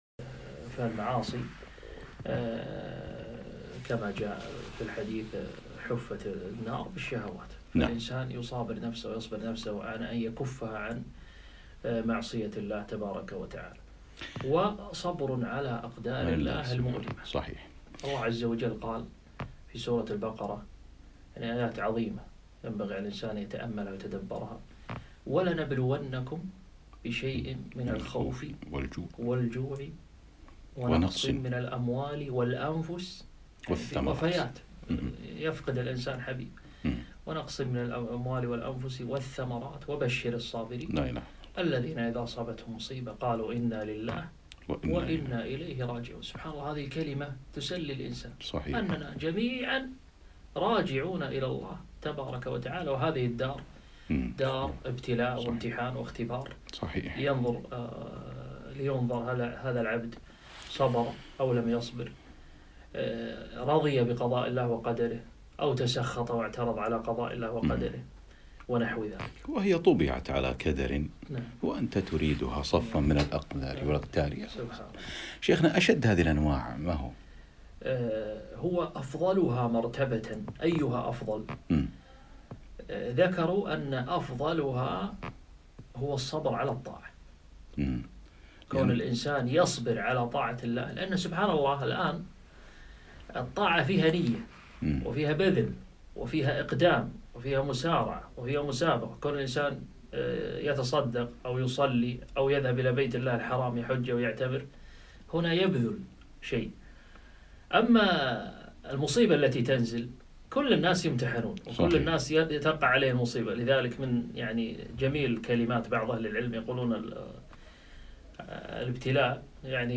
الـصـبـر - لقاء إذاعي برنامج طريق الإيمان